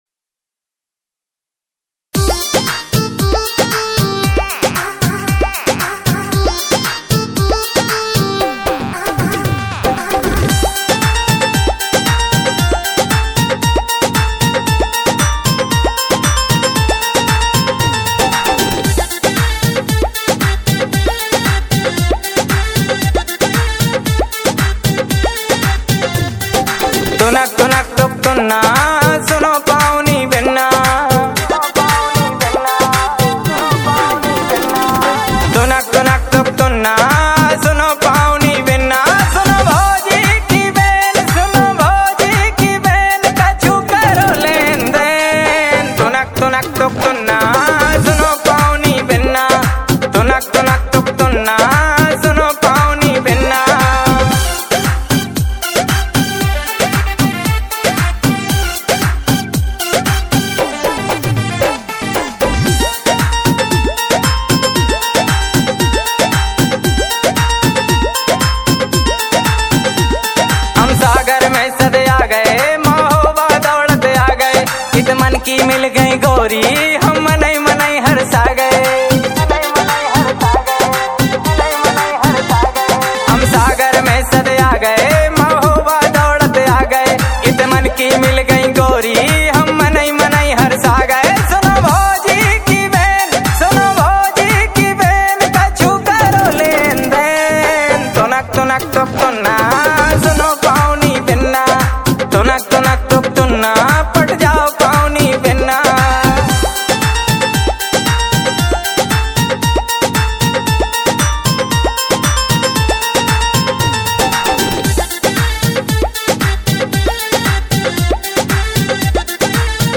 Rajsthani Simple Songs